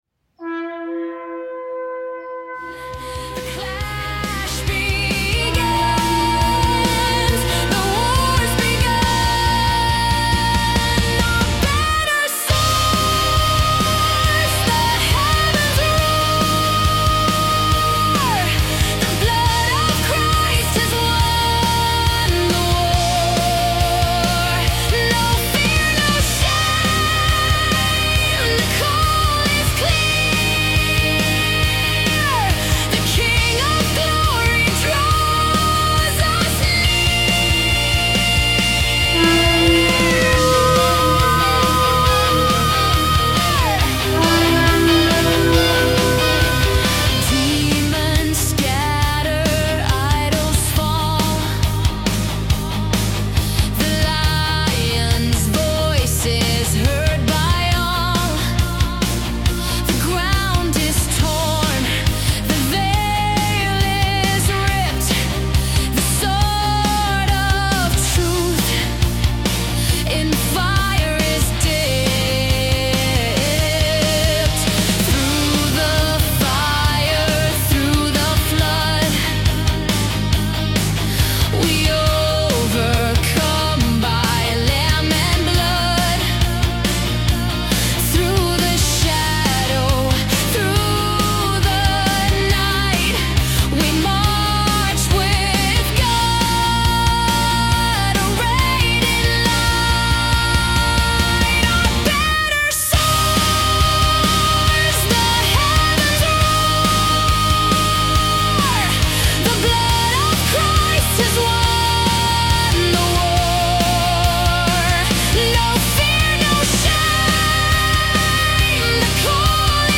powerful Christian rock anthem
• Genre: Christian Rock / Worship Rock